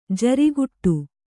♪ jariguṭṭu